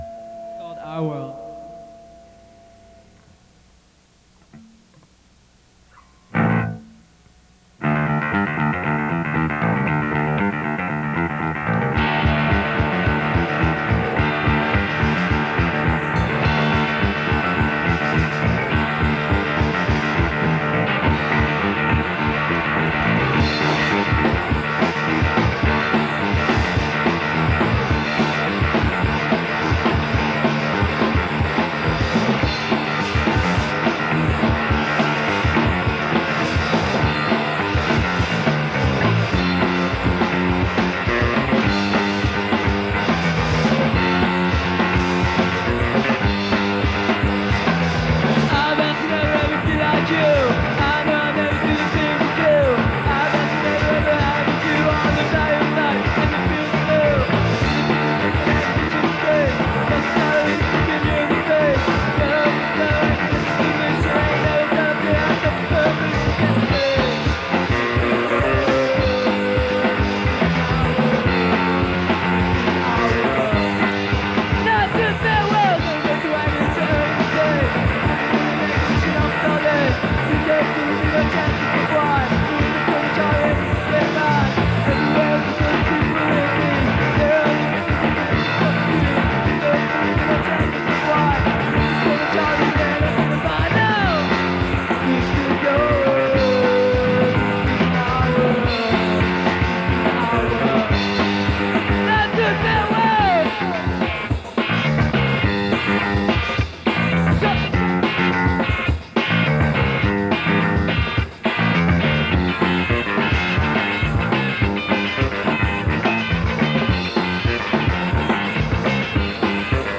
heavy Reggae influence